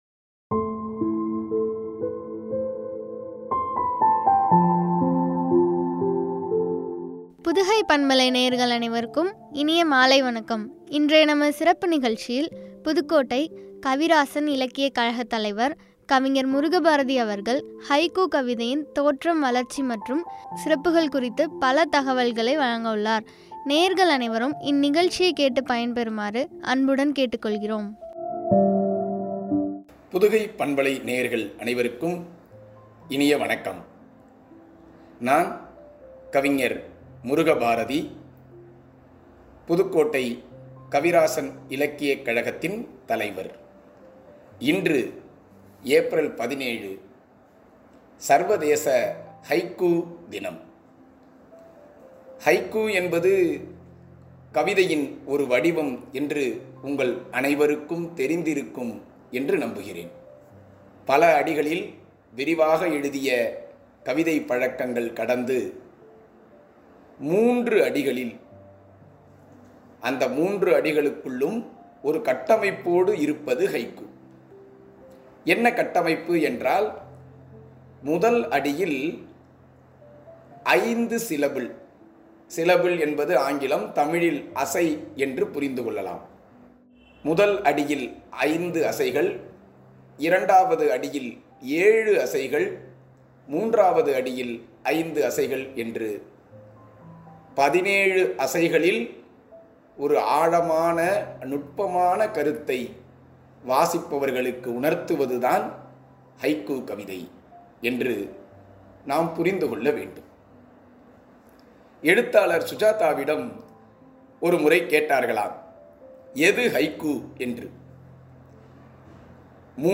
வளர்ச்சி மற்றும் சிறப்புகள் குறித்து வழங்கிய உரையாடல்.